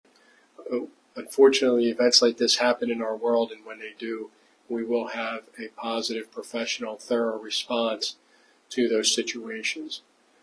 At a press conference Monday afternoon, state troopers provided more information about a stabbing incident that happened in Burrell Township on Sunday evening.
District Attorney Robert Manzi talked about how the investigation is being handled.